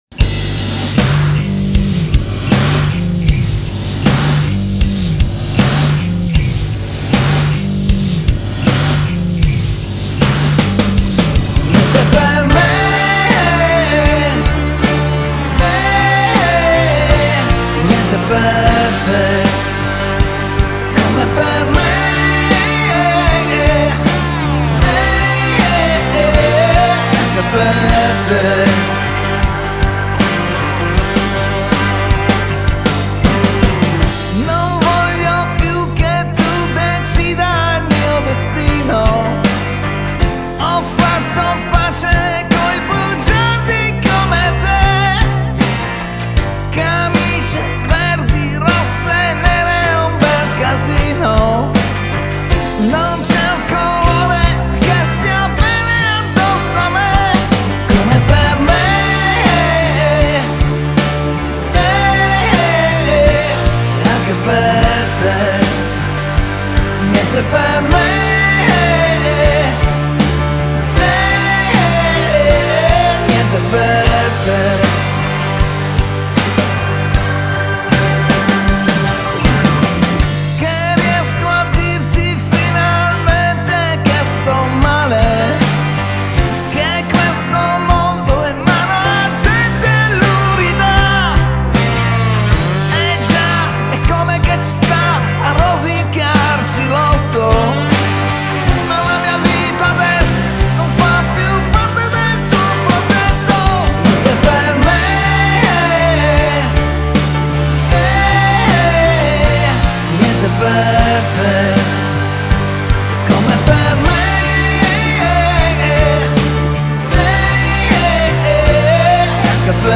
Genre: Pop, Pop Rock